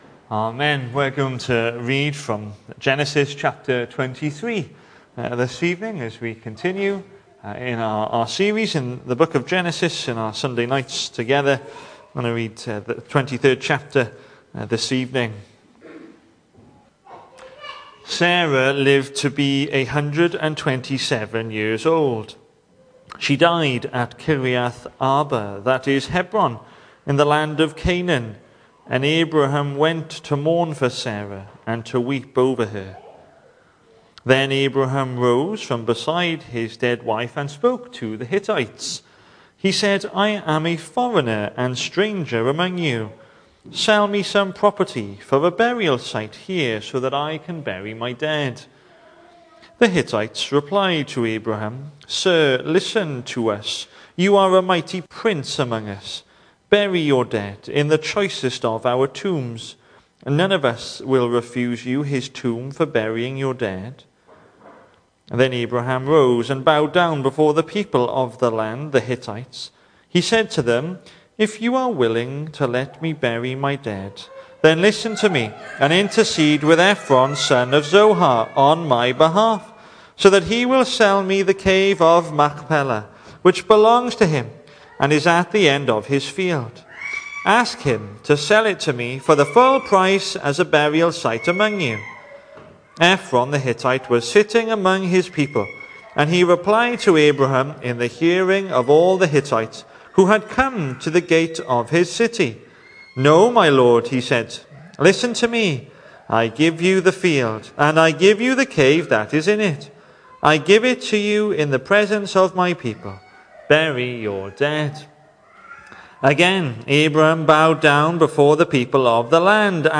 The 8th of March saw us hold our evening service from the building, with a livestream available via Facebook.
Sermon